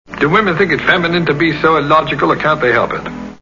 Charade Movie Sound Bites